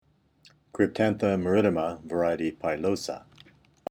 Pronunciation/Pronunciación:
Cryp-tán-tha ma-rí-ti-ma var. pi-ló-sa